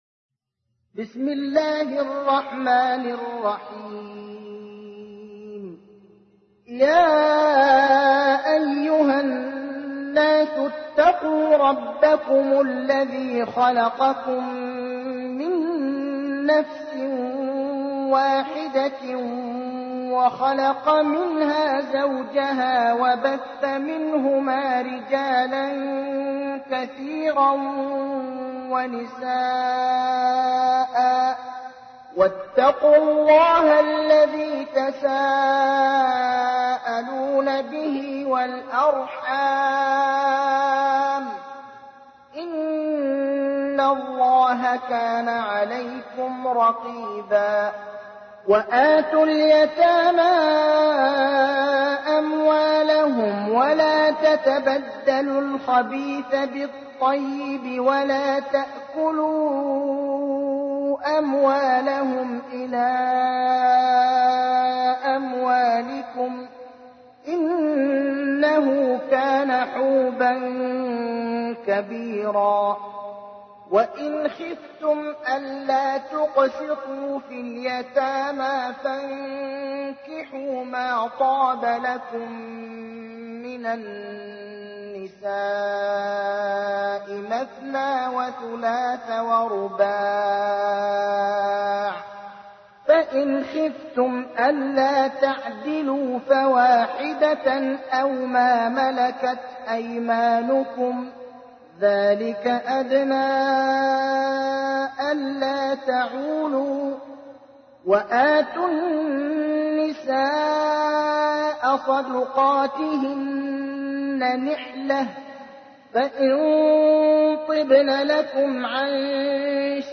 تحميل : 4. سورة النساء / القارئ ابراهيم الأخضر / القرآن الكريم / موقع يا حسين